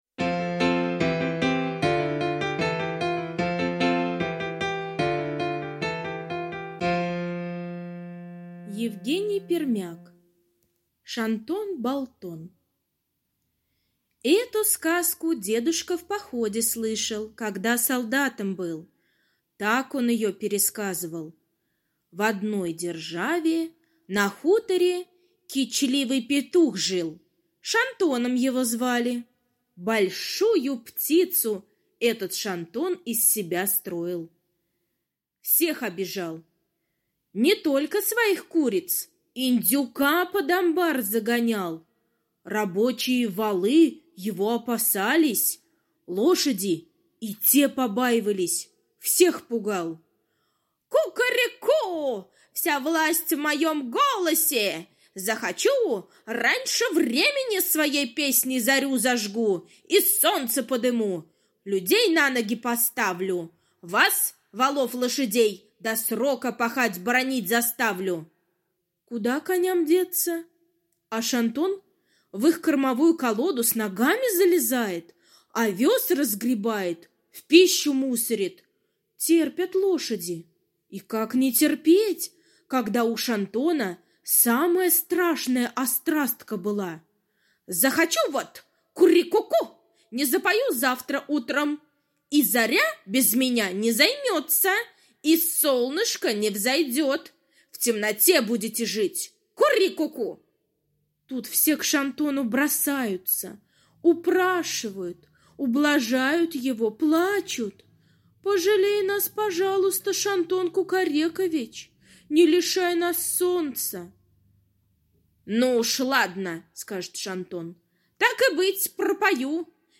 Шантон-Болтон — аудиосказка Пермяка Е. Сказка про кичливого петуха, который строил из себя большую птицу и весь двор в страхе держал...